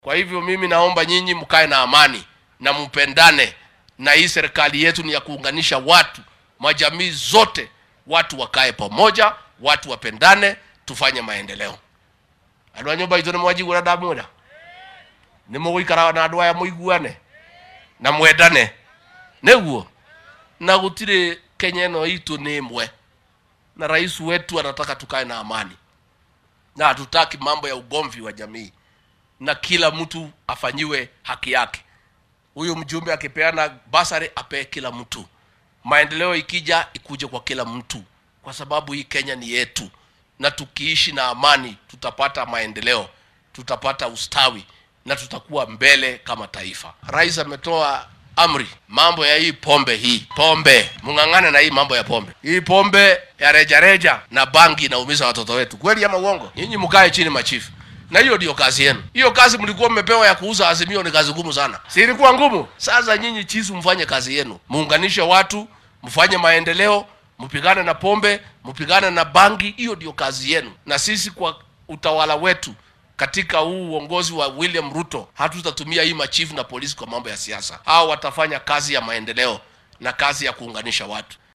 Xilli uu ku sugnaa ismaamulka Kericho ayuu Gachagua xusay in madaxweynaha dalka uu dejiyay qorsho ballaaran oo lagu horumarinayo qaranka.
Gachagua-Kericho.mp3